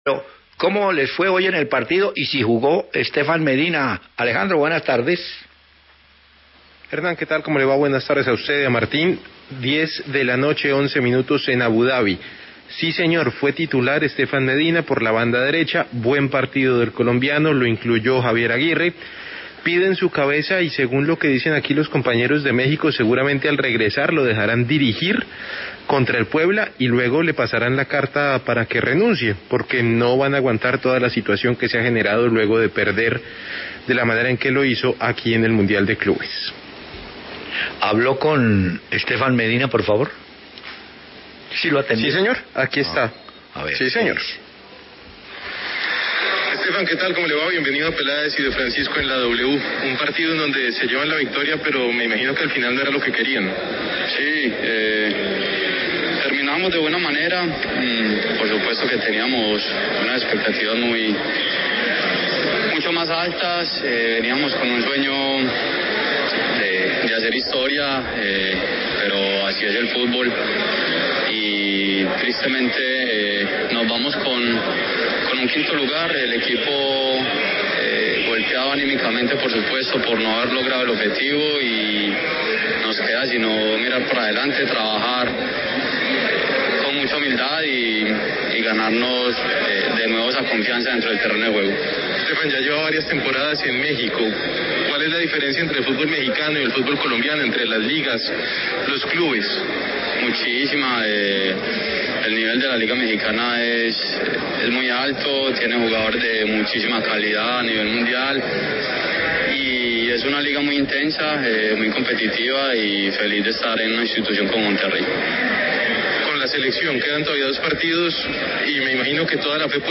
Stefan Medina habló con Peláez y De Francisco en La W luego de la victoria de su equipo, el Monterrey, ante el Al-Jazira, en el Mundial de Clubes.